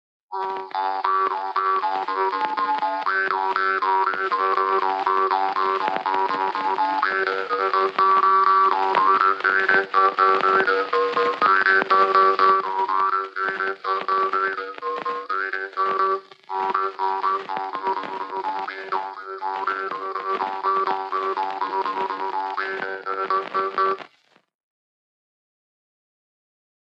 Polka "Nooriku ärasaatmine"